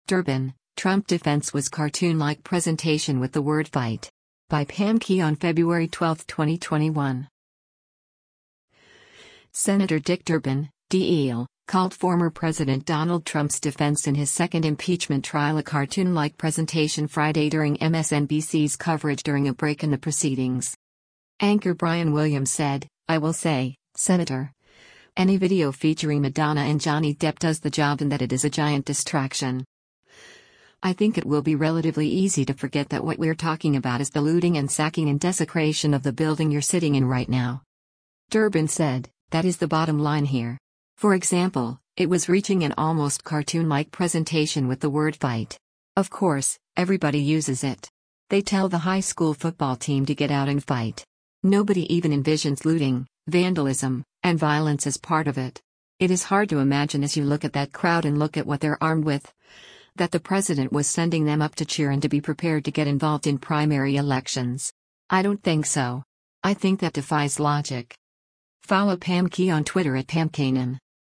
Senator Dick Durbin (D-IL) called former President Donald Trump’s defense in his second impeachment trial a “cartoon-like presentation” Friday during MSNBC’s coverage during a break in the proceedings.